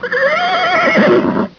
horse.au